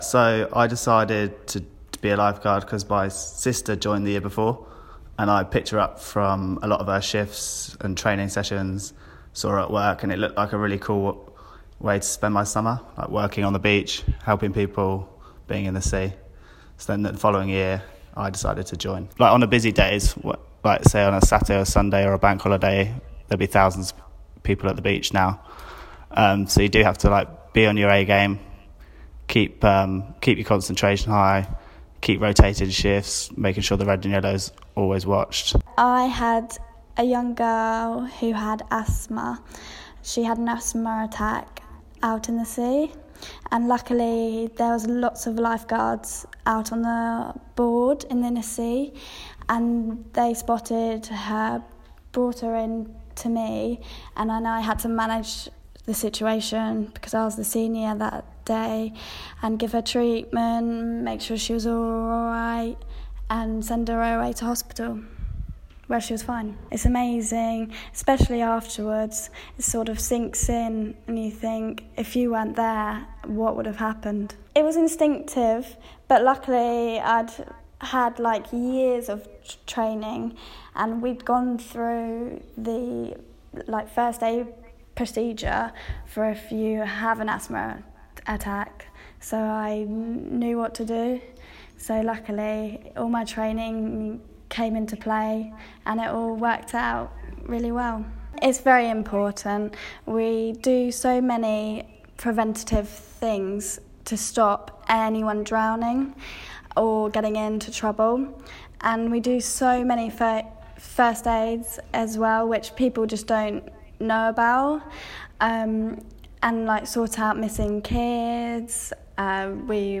LISTEN: Two siblings from Kent say being a lifeguard is harder than it seems - 30/07/18
A brother and sister from Kent who'll be patrolling the beaches this summer say lifeguards have more responsibilites than people realise.